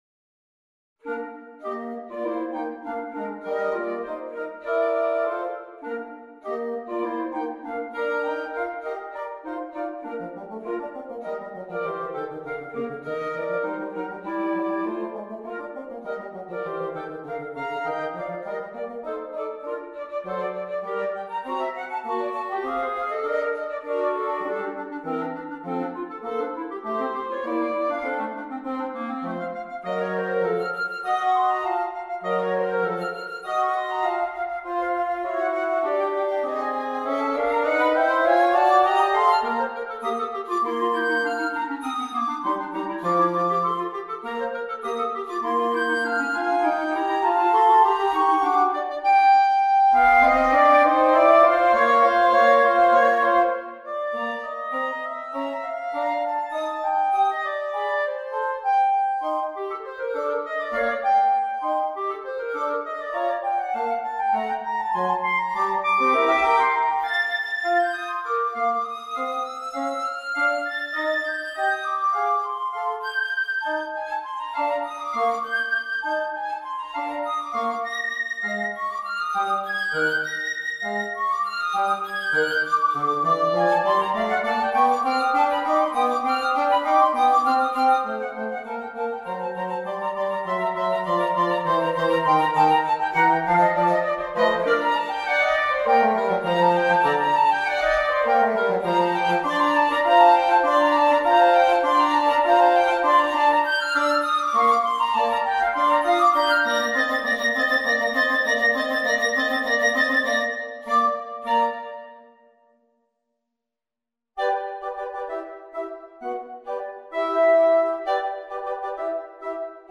Ecco la celebre suite completa arrangiata per trio.